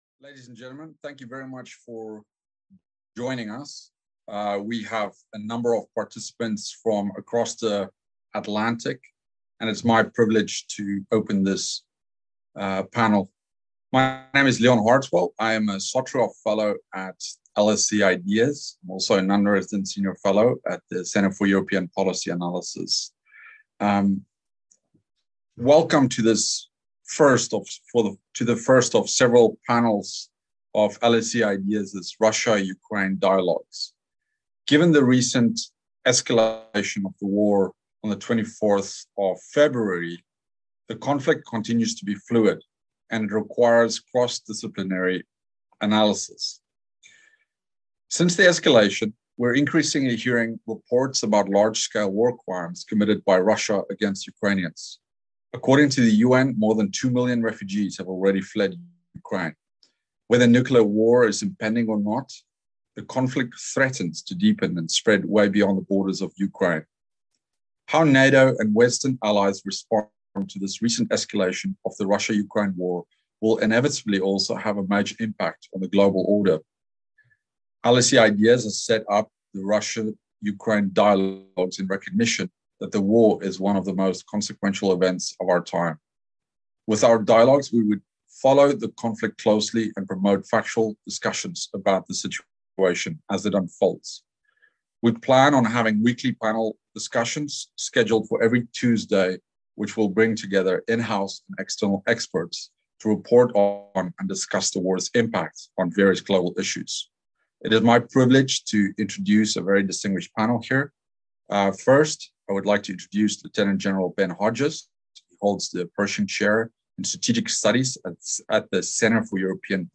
This LSE IDEAS webinar provided a status update on the Russo-Ukrainian War on 8 March.
This week’s panel discuss the following issues from an international perspective: